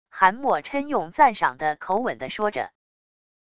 韩墨琛用赞赏的口吻的说着wav音频